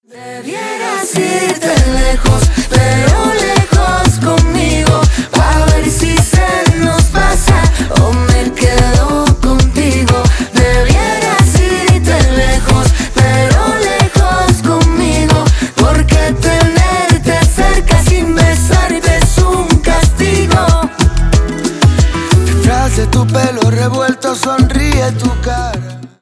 латинские
поп